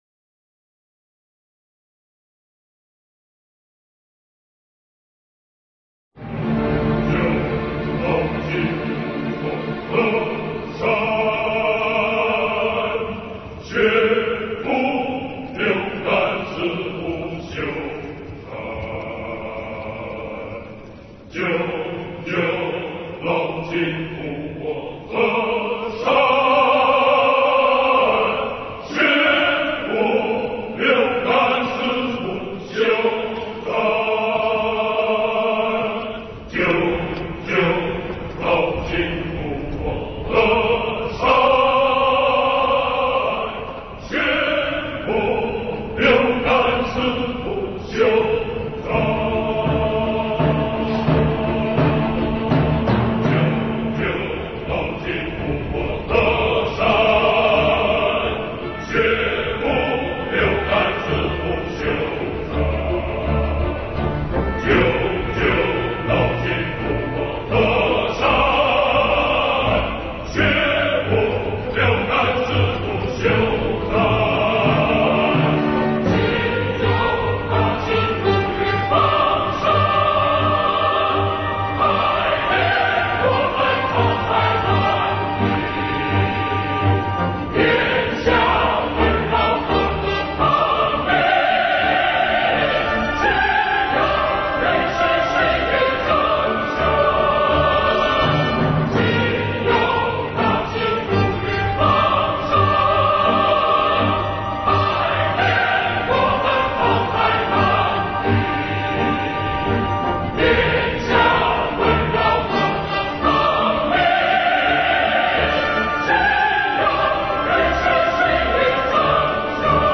震撼！！！